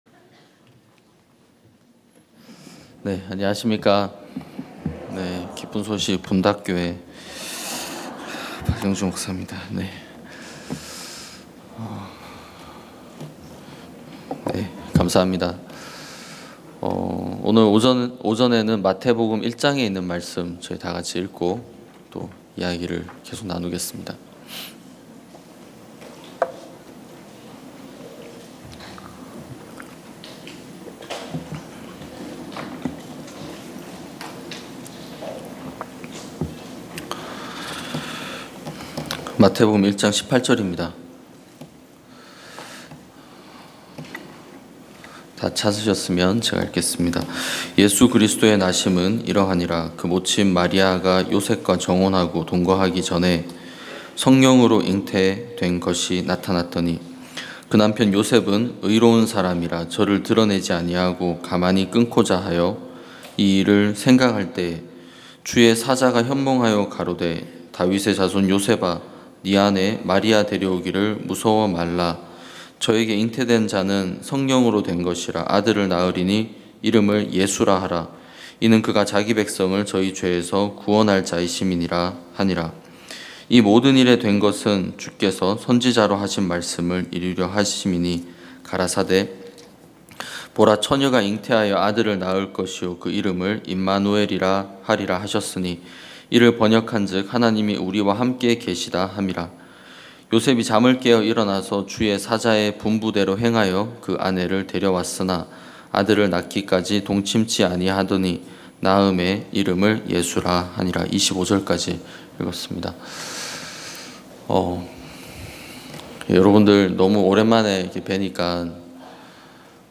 2022 여름 캠프(대덕) 2차 #3 예수 그리스도의 나심은 이러하니라 좋아요 즐겨찾기 프로그램 소개 프로그램 응원 공유 다운로드 태그 이 콘텐츠를 보시고 떠오르는 단어는 무엇입니까?